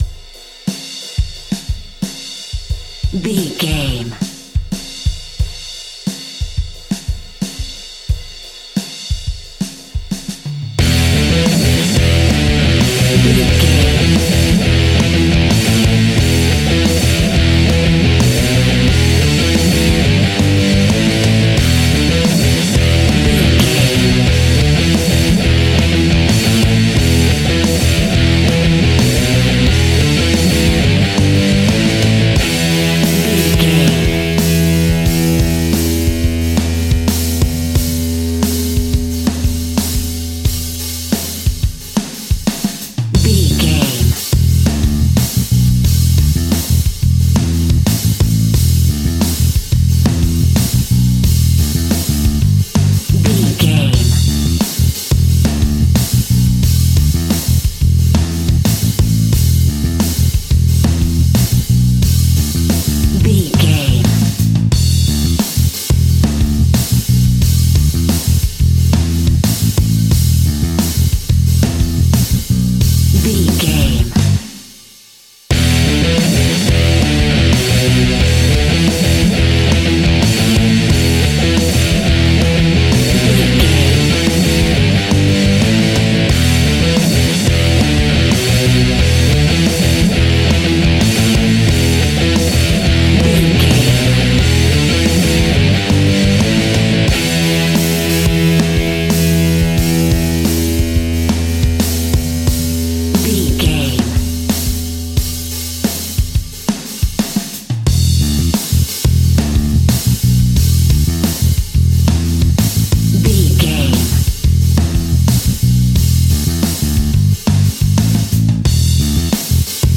Hard and Powerful Metal Rock Music Cue Alt Mix.
Epic / Action
Aeolian/Minor
hard rock
heavy metal
blues rock
instrumentals
Rock Bass
heavy drums
distorted guitars
hammond organ